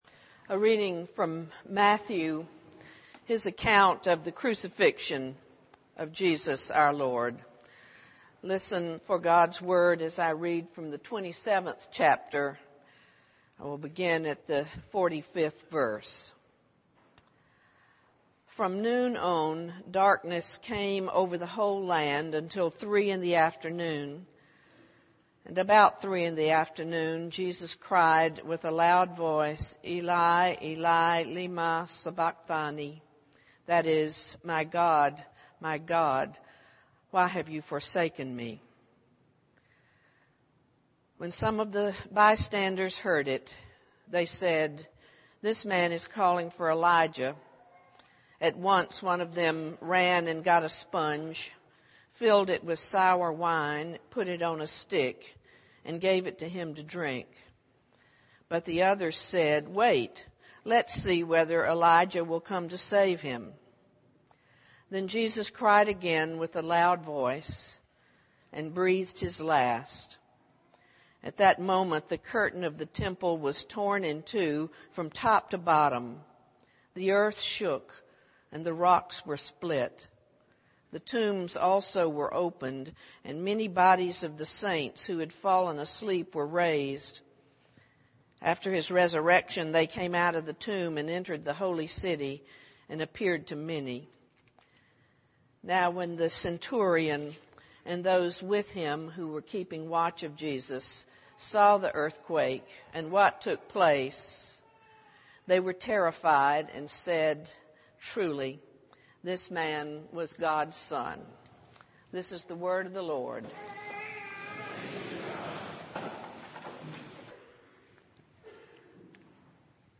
worship_mar09_sermon.mp3